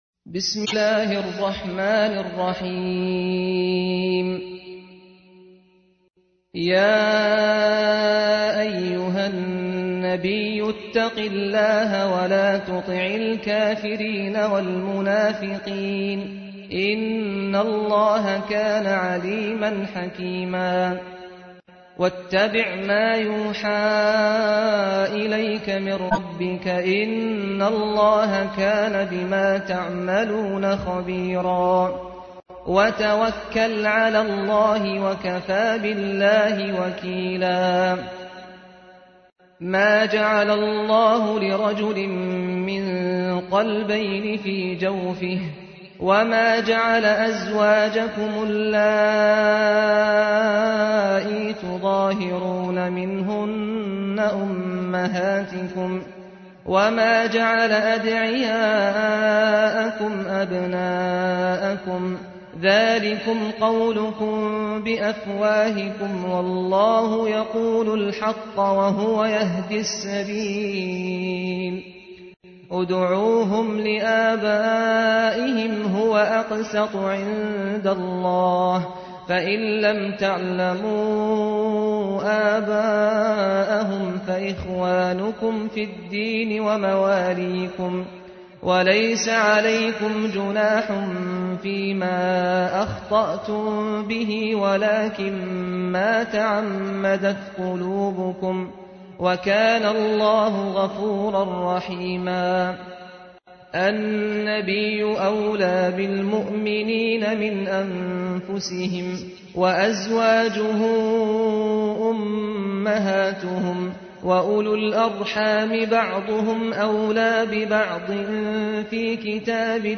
تحميل : 33. سورة الأحزاب / القارئ سعد الغامدي / القرآن الكريم / موقع يا حسين